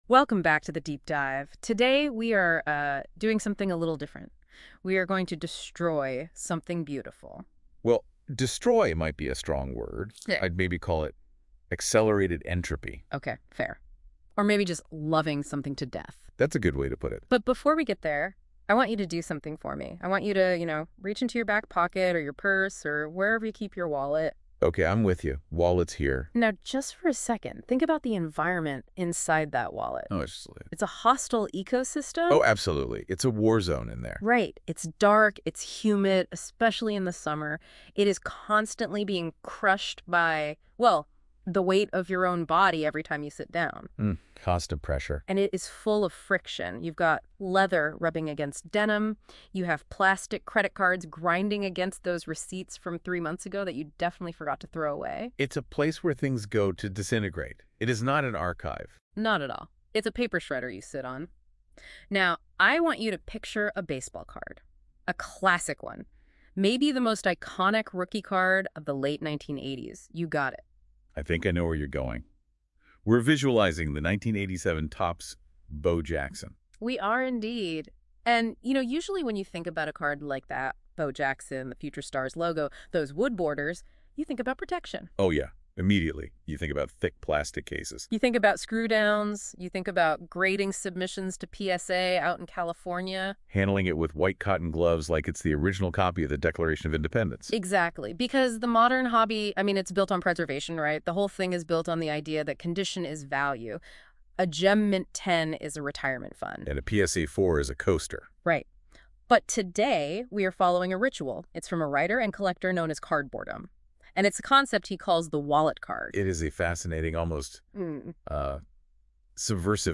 This exploration of the 1987 Topps Bo Jackson baseball card is the latest in this series . Try this audio discussion from a pair of bots if you don’t want to spend time with so much text.